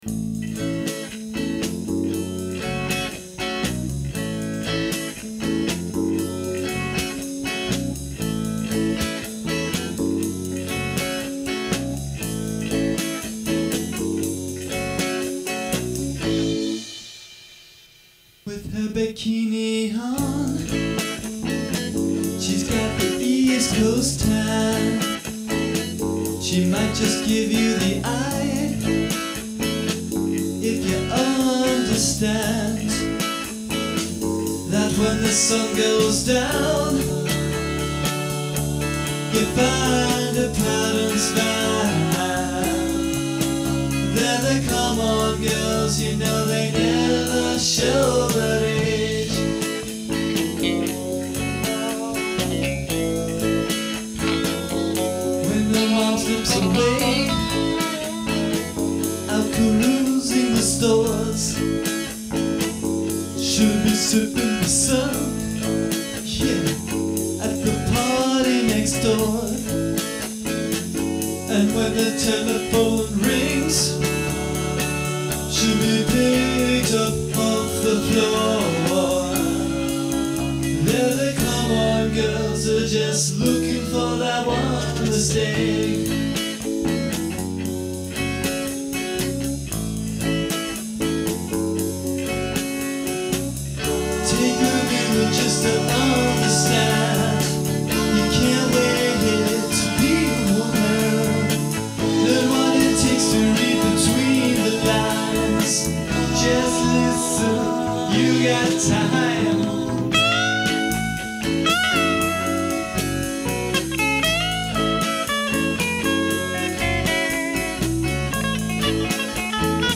percussion
bass guitar
keyboards
guitar, vocals